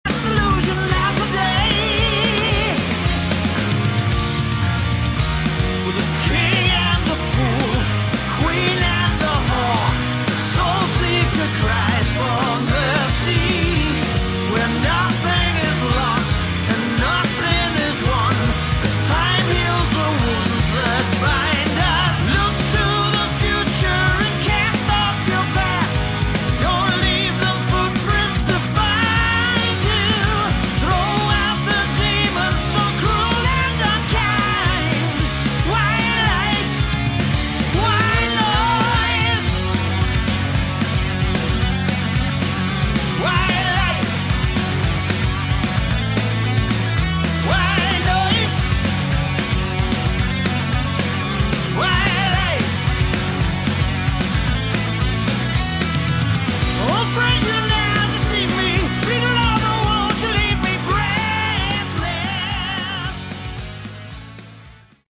Category: Hard Rock
Vocals
Guitars